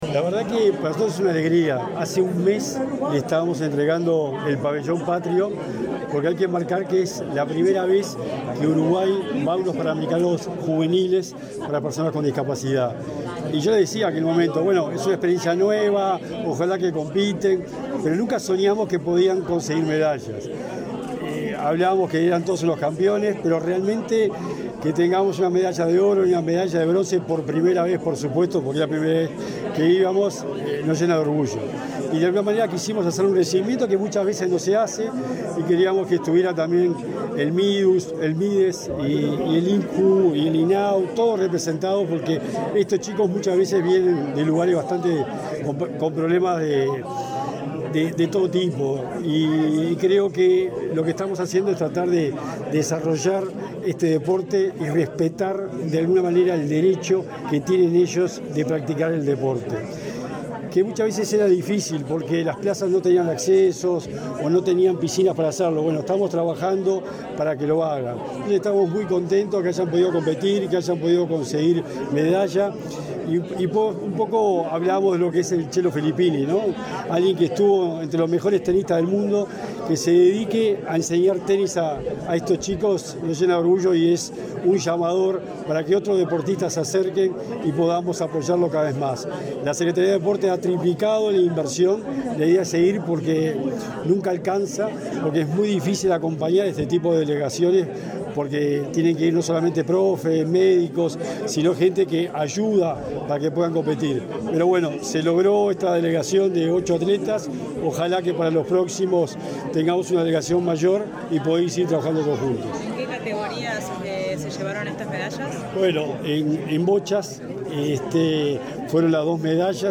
Declaraciones del secretario nacional del Deporte
El ministro de Desarrollo Social, Martín Lema; el secretario nacional del Deporte, Sebastián Bauzá, y el presidente del Instituto del Niño y el Adolescente del Uruguay (INAU), Pablo Abdala, participaron en el homenaje a los atletas uruguayos que representaron a Uruguay en los Juegos Parapanamericanos Juveniles Bogotá 2023. Antes Bauzá dialogó con la prensa.